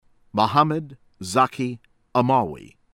AL-ZARQAWI, AHMAD AH-mahd   al   zahr-KAW-wee